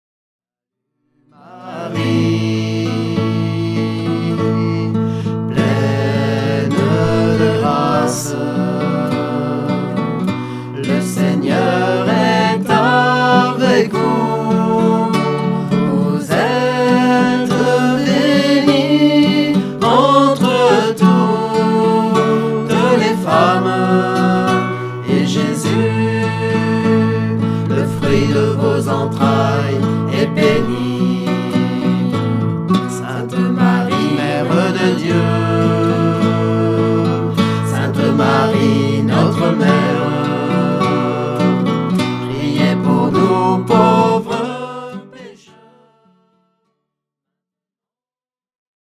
Chaque mystère est accompagné de chants pour la louange
Format :MP3 256Kbps Stéréo